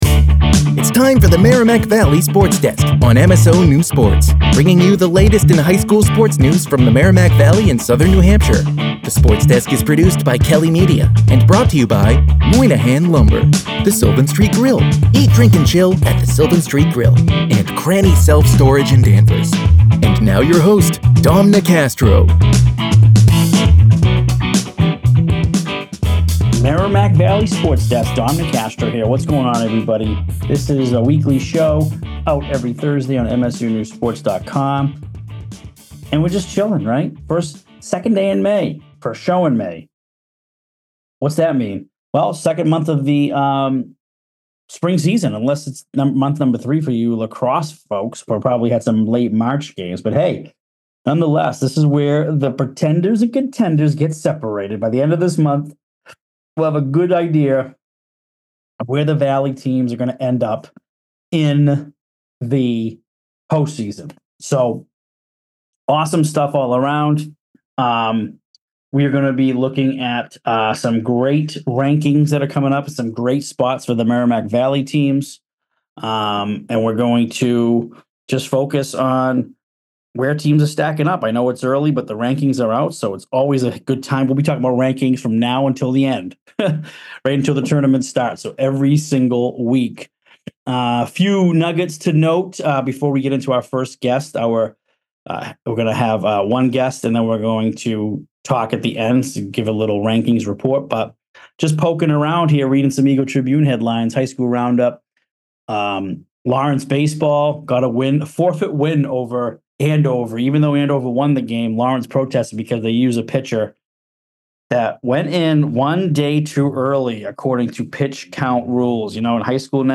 Feature Guest